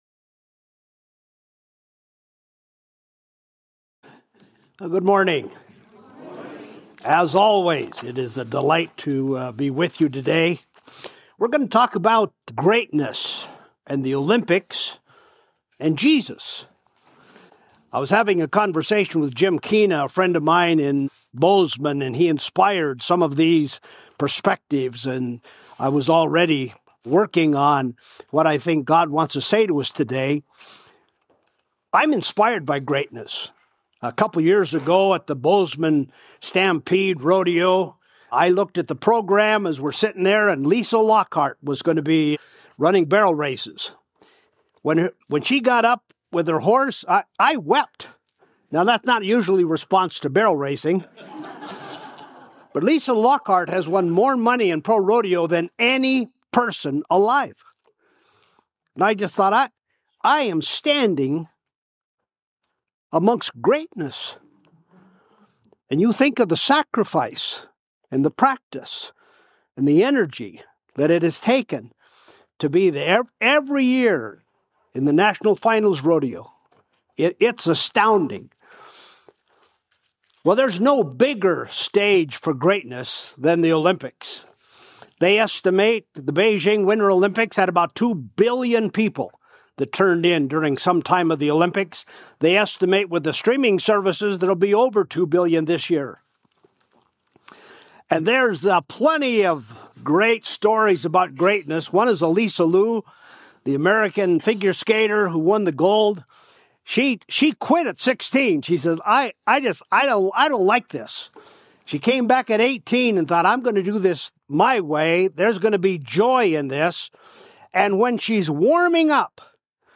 Special Guest Speaker